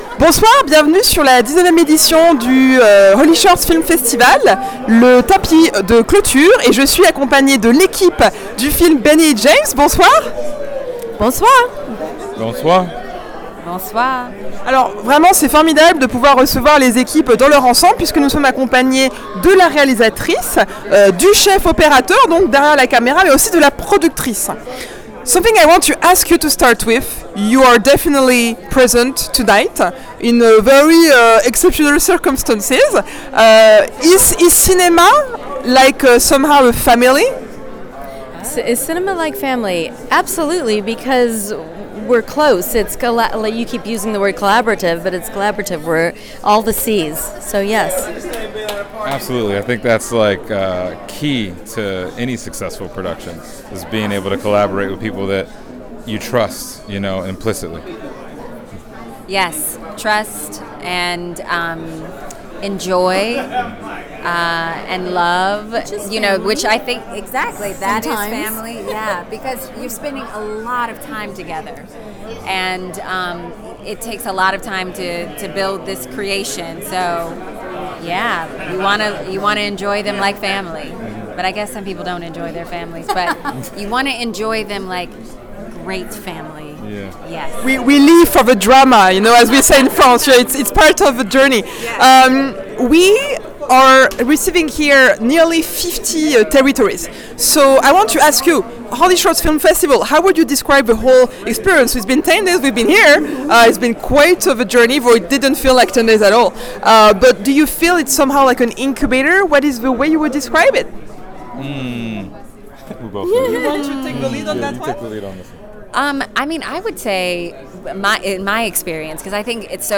Au cœur du HollyShorts Film Festival 2023, "Benny & James" a trouvé un espace unique pour célébrer la diversité des expériences artistiques.